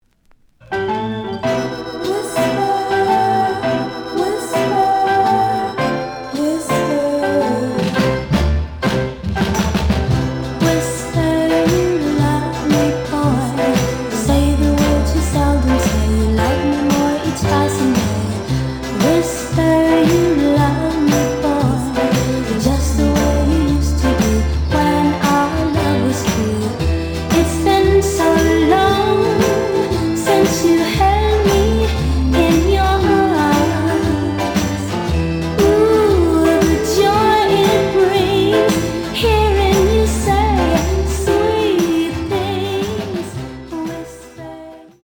The audio sample is recorded from the actual item.
●Genre: Soul, 60's Soul
But plays good.